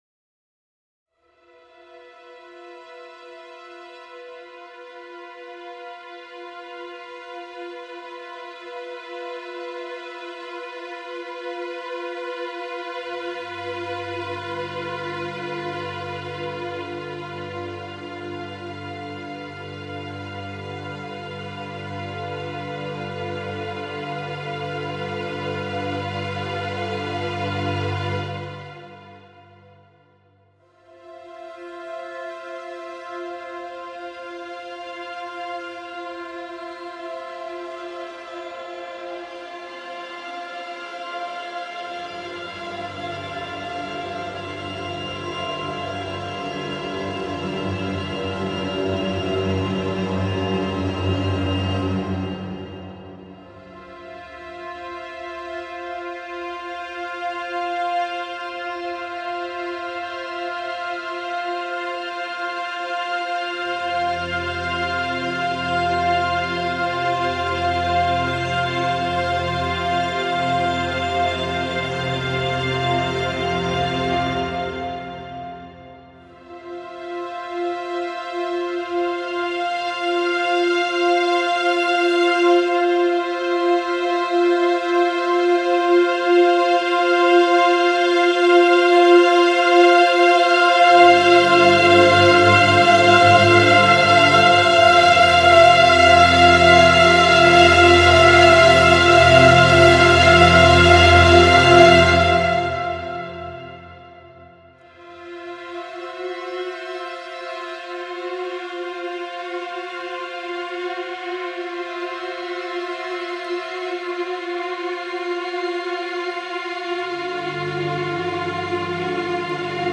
Aufgenommen wurde einmal mehr in den Londoner Air Studios und zwar: 16 erste Violinen, 14 zweite Violinen, 12 Violas, 10 Cellos und 8 Bässe.
Aufgenommen wurden lange Noten. Allerdings nicht, wie üblich, konventionell, sondern mit Zwischentönen und Geräuschen der Instrumente, um nur einige Besonderheiten zu nennen. Dadurch soll das Klangbild lebendiger wirken.